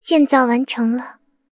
追猎者建造完成提醒语音.OGG